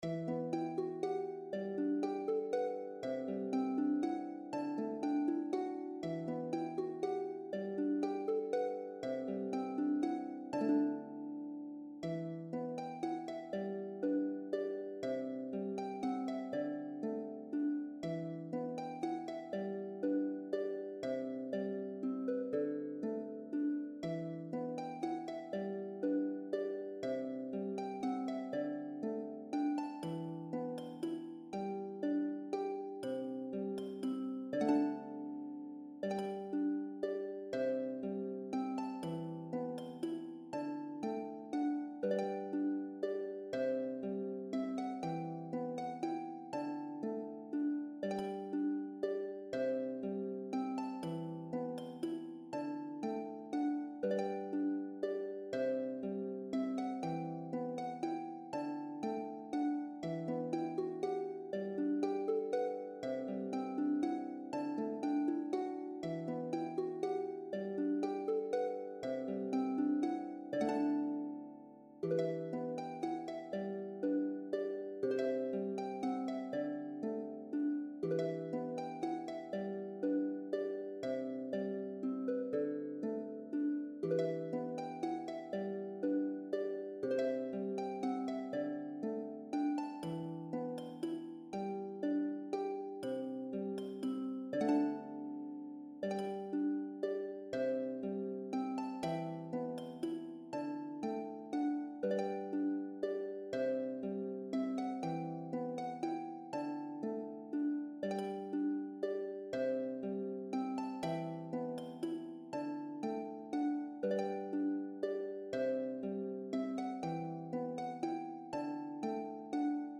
for solo lever or pedal harp. An uplifting melody